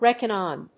アクセントréckon on…